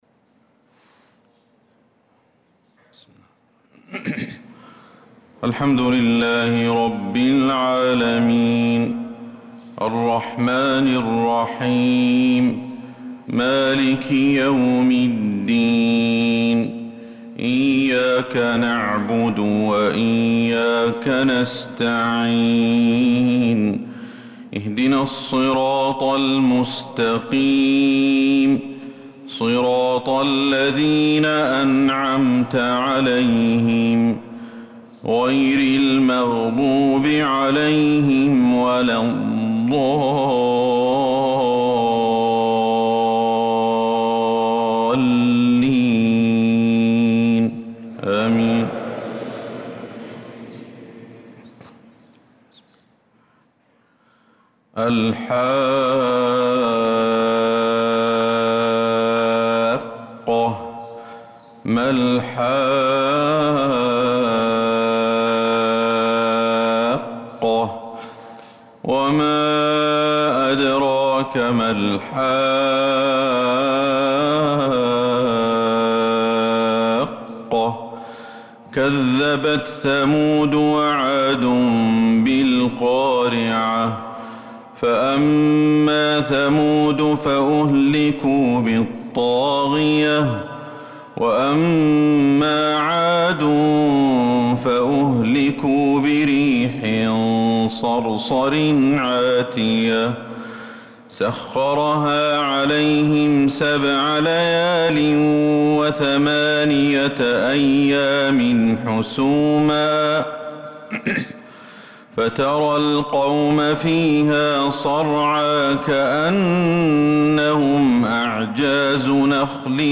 صلاة الفجر للقارئ أحمد الحذيفي 2 ذو الحجة 1445 هـ
تِلَاوَات الْحَرَمَيْن .